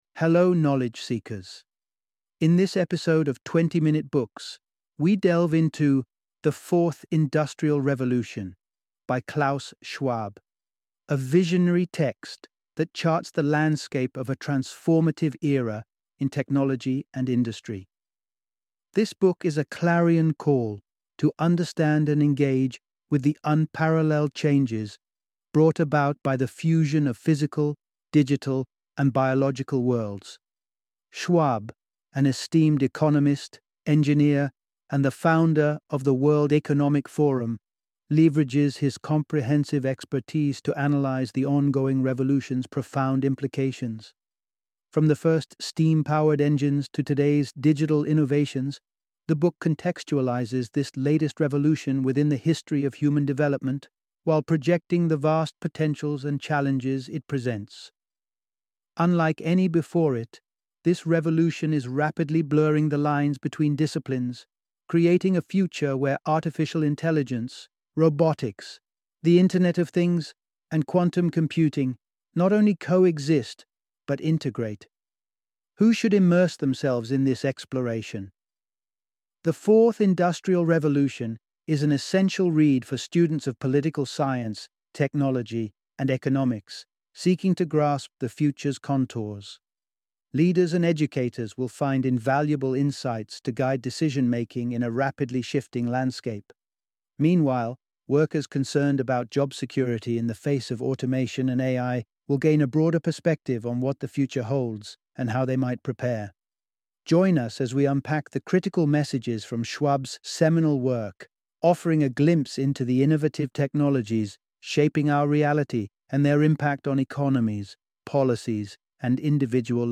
The Fourth Industrial Revolution - Audiobook Summary